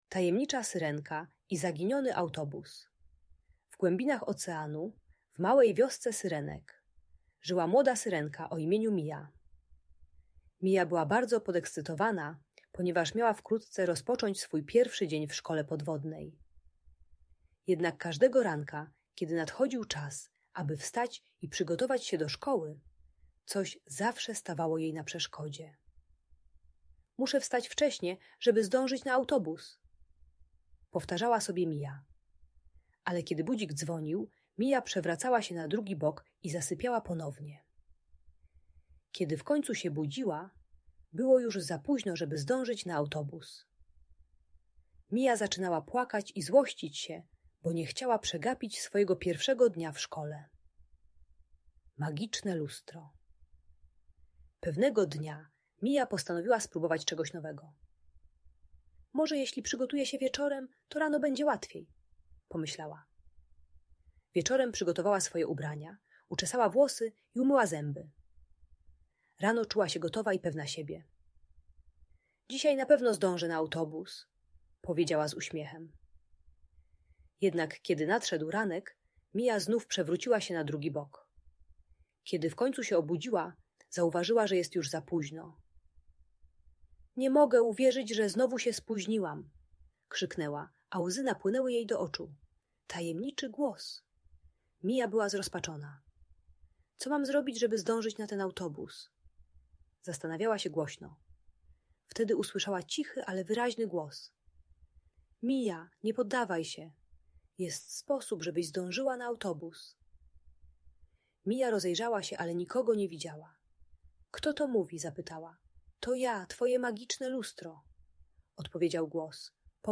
Tajemnicza Syrenka i Zaginiony Autobus: magiczna opowieść - Audiobajka dla dzieci